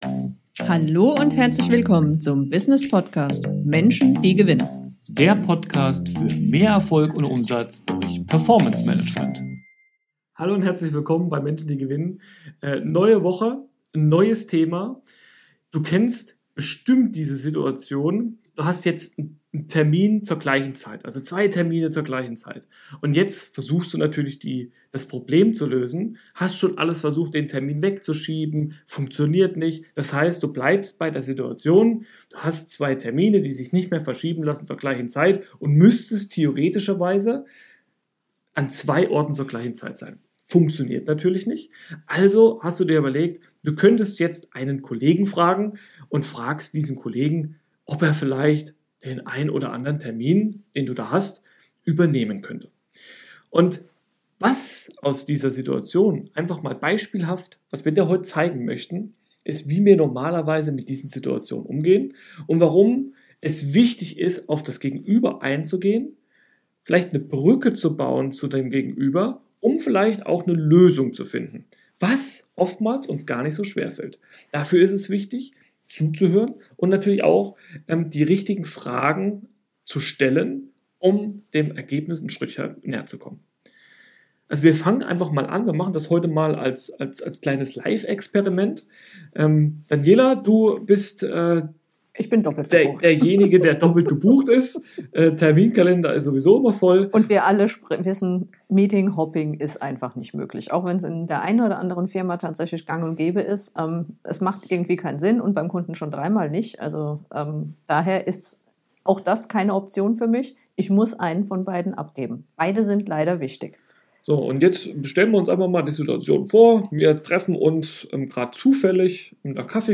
In einem Live Rollenspiel zeigen wir dir, wie du dein Gegenüber davon überzeugst Ja zu sagen.